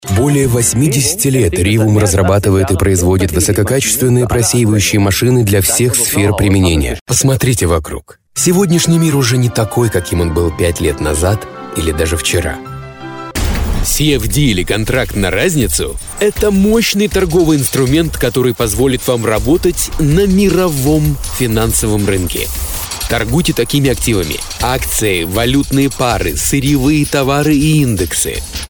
Kein Dialekt
Sprechprobe: Industrie (Muttersprache):
Studio Equipment: 4×6 Double-wall booth by “Whisper Room” Microphones: Rode NT2000 Condencer Mic. AT5040 Condencer Mic. Sennhieser 416 Condencer Mic. Sennhieser 441U Dynamic Mic. Shure SM7B Dynamic Mic. Other stuff: Presonus “Studio Live” mixer board (Firewire) MacPro Computer Source Connect, Phone/Skype patch. FiOS Internet Connection